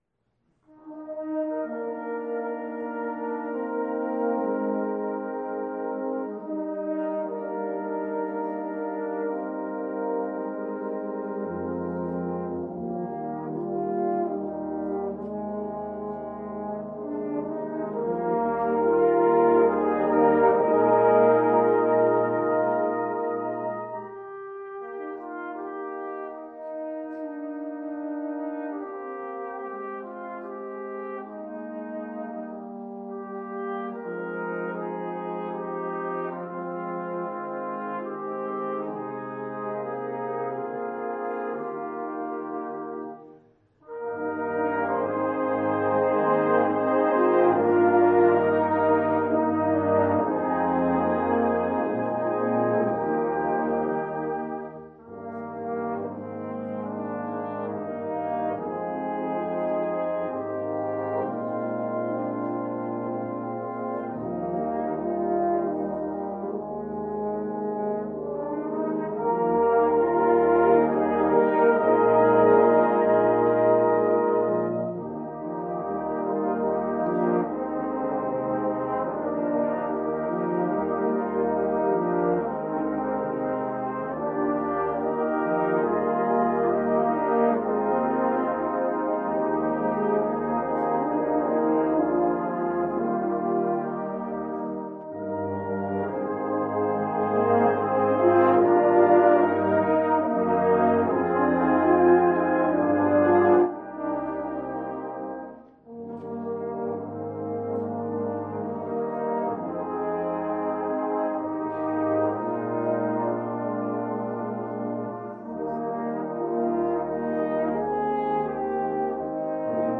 (Chorus Arrangement) This meditative piece